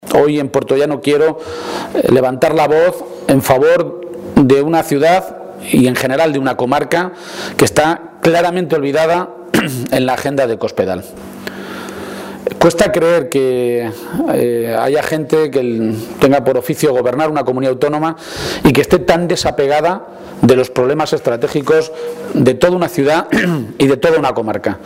García-Page ha realizado estas declaraciones en Puertollano, localidad en la que esta tarde tiene previsto reunirse con colectivos y sindicatos para analizar la tremenda situación por la que está atravesando la ciudad industrial.